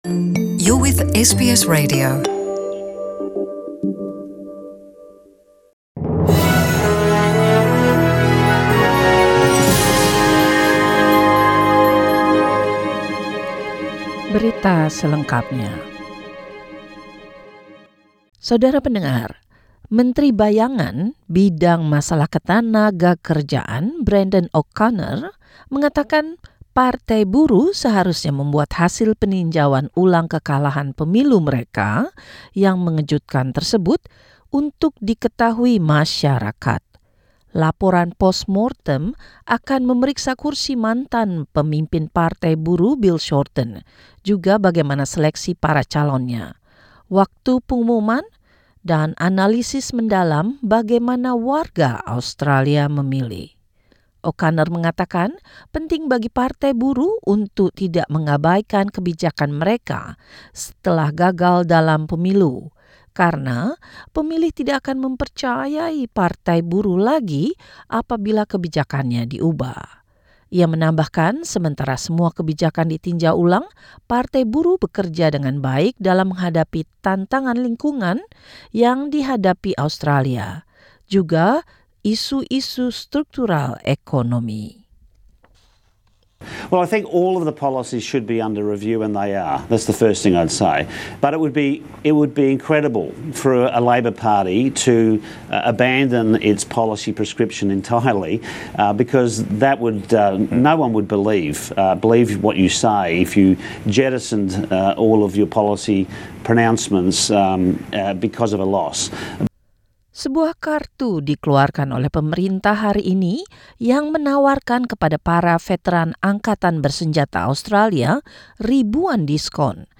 Warta Berita Radio SBS dalam Bahasa Indonesia - 3 November 2019